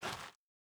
Bare Step Gravel Medium D.wav